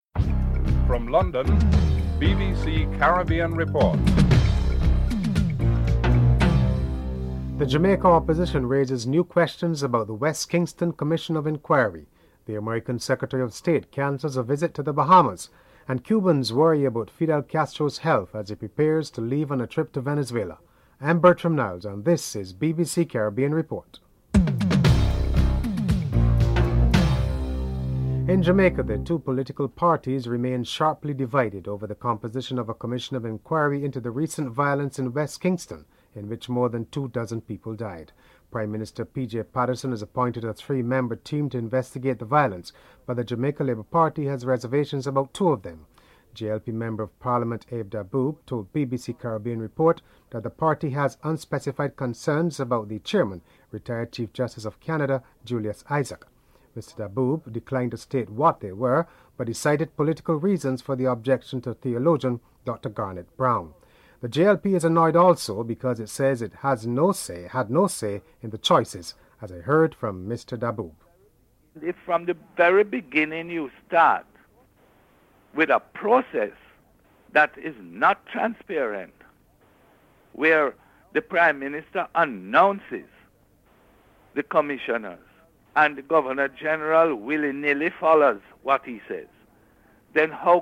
The British Broadcasting Corporation
1. Headlines (00:00-00:27)